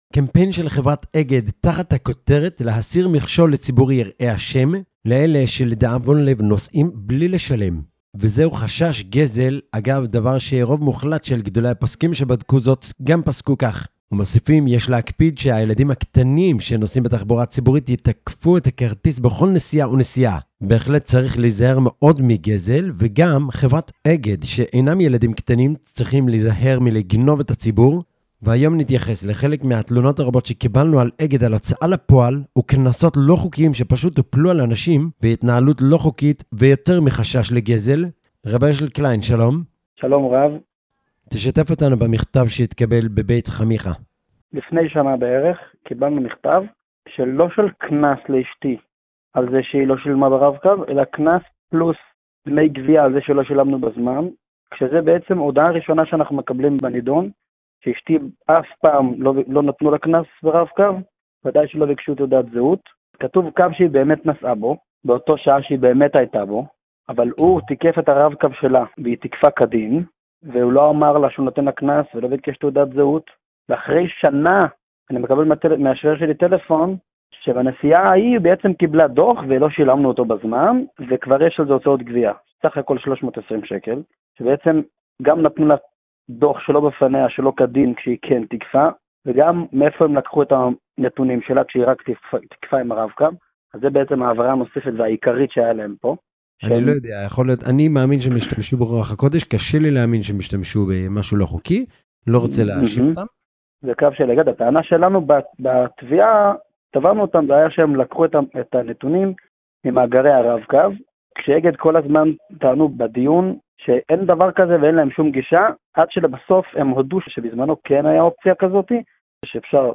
ראיון עם עדויות אותנטיות של אנשים שנעשקו ע"י אגד שלא כחוק לחצ/י כדי להרחיב...